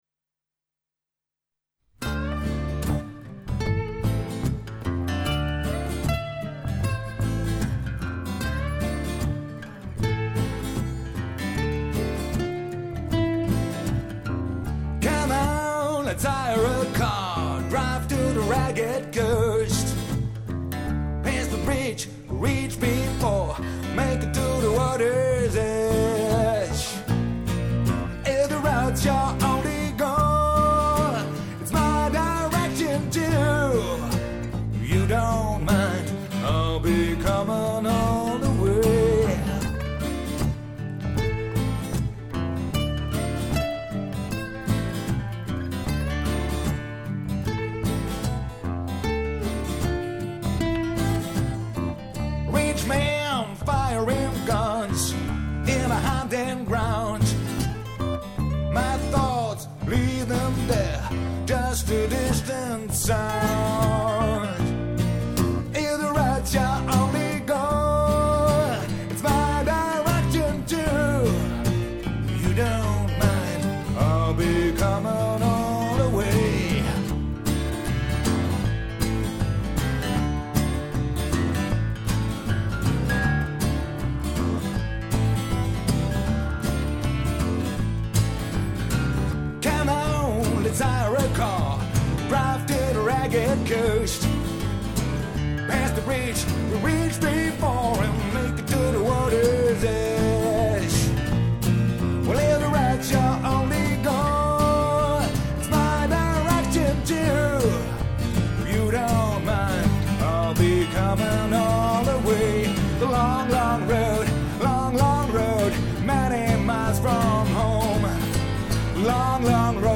harten Rock‘n‘Roll-Bands
Guitar Lead
Bass
Drums